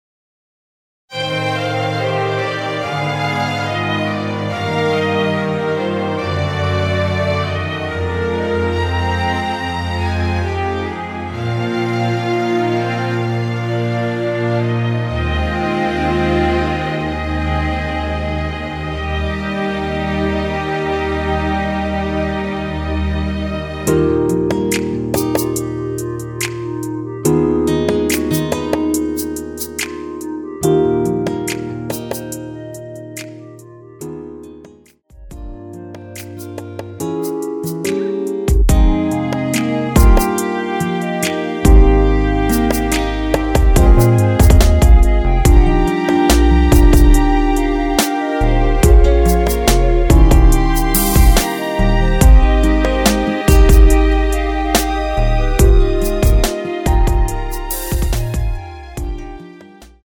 원키에서(-1)내린 멜로디 포함된 MR입니다.
주 멜로디만 제작되어 있으며 화음 라인 멜로디는 포함되어 있지 않습니다.(미리듣기 참조)
Eb
앞부분30초, 뒷부분30초씩 편집해서 올려 드리고 있습니다.
중간에 음이 끈어지고 다시 나오는 이유는